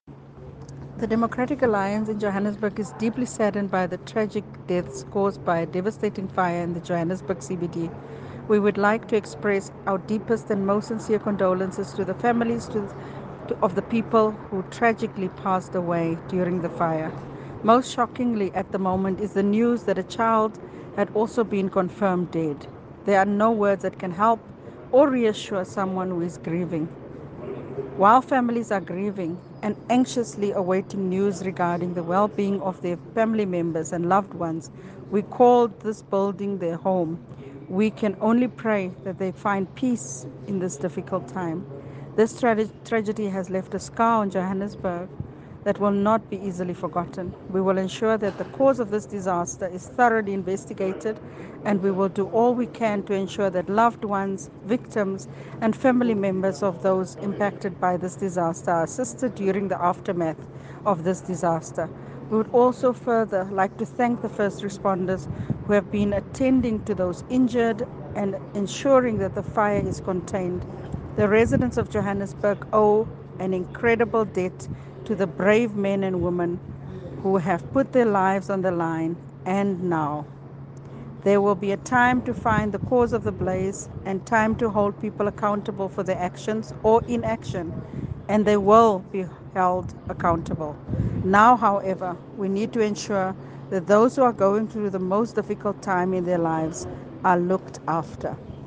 Issued by Cllr Belinda Kayser-Echeozonjoku – DA Johannesburg Caucus Leader
Please find a soundbite